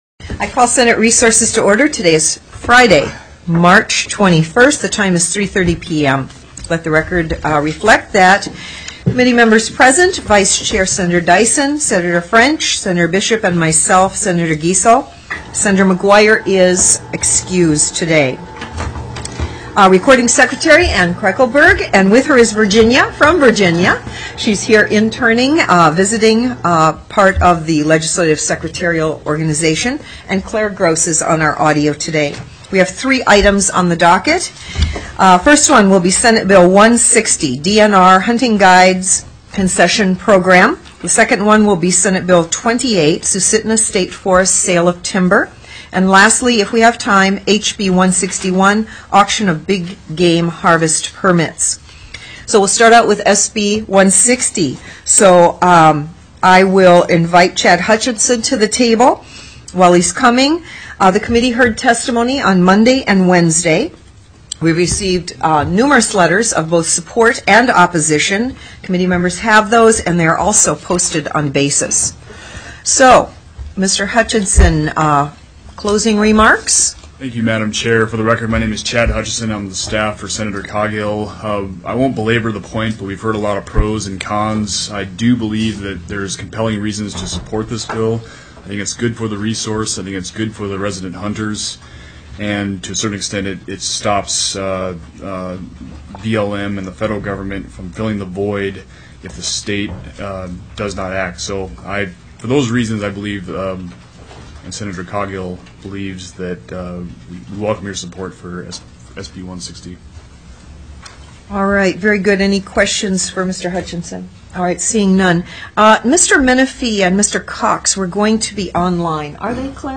SB 28 SUSITNA STATE FOREST; SALE OF TIMBER TELECONFERENCED
Public Testimony